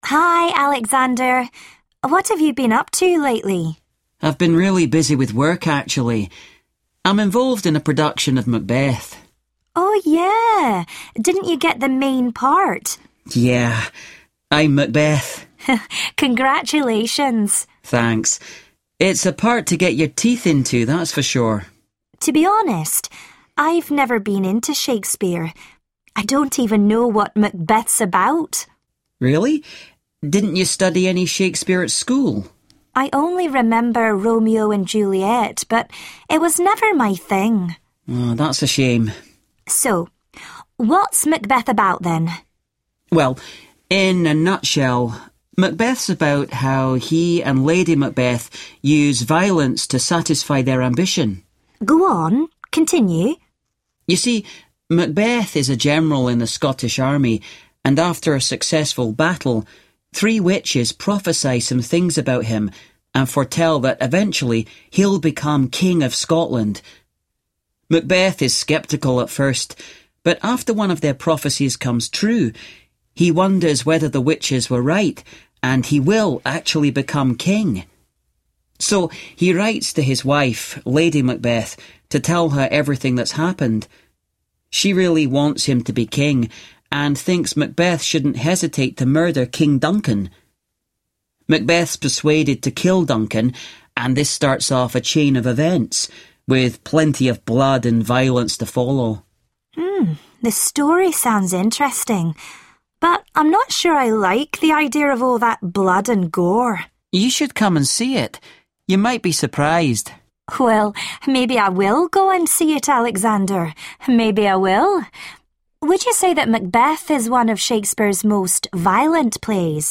They then listen to two people discussing violence in Shakespeare's work and identify some examples of violence.